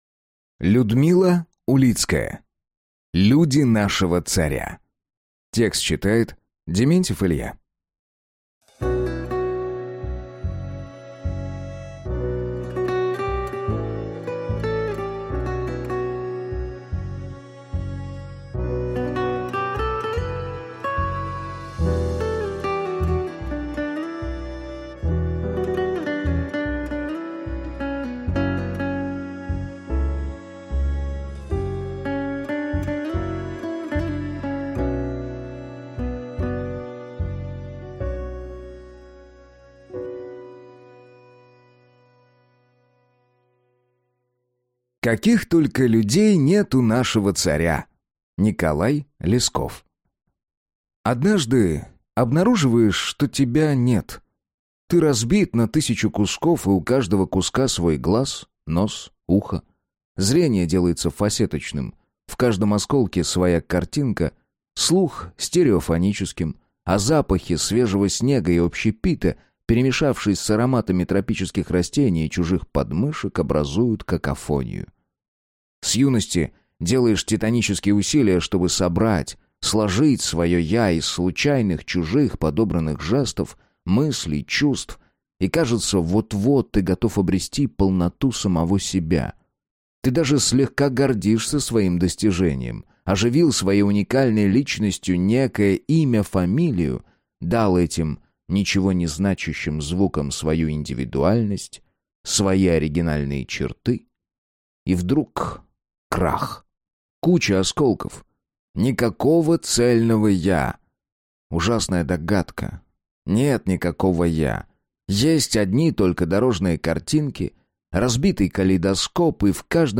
Аудиокнига Люди нашего царя (сборник) | Библиотека аудиокниг